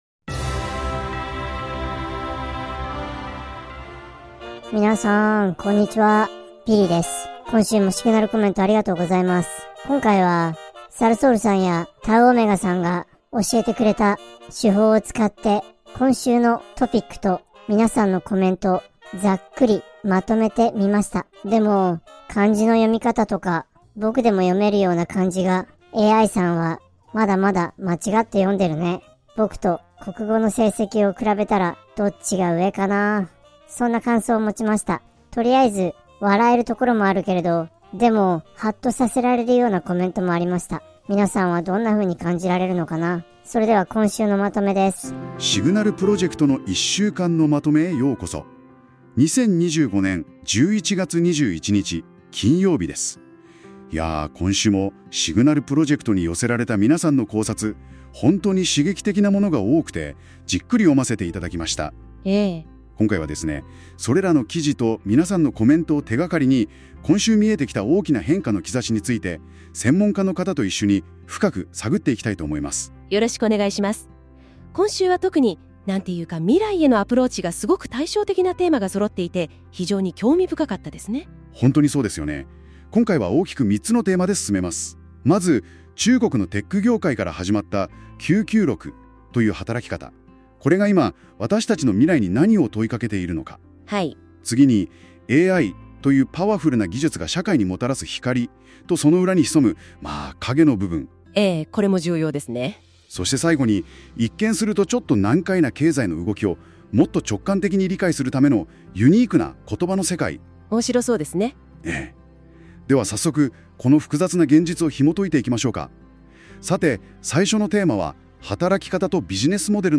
本エピソードは、AIによる音声生成機能を用いた実験的な運用としてお届けしています。
AIプレゼンターによる日本語の運用能力には、時に予期せぬ、あるいは少し笑ってしまうような表現が含まれているかもしれません。しかし、それを差し引いても、今週の主要トピックとSignal Projectメンバーの皆様の深い洞察に満ちたコメントが、うまく抽出され、整理されていると思います。